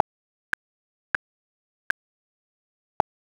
Изначально в одном темпе доли равны, но при плавном изменении они становятся разными - это видно по сетке и слышно по метроному (который можно отрендерить и измерить, см.вложение).
Вложения metr.wav metr.wav 1,1 MB · Просмотры: 85